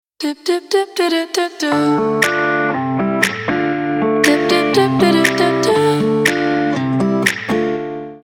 • Качество: 320, Stereo
женский голос
dance
Melodic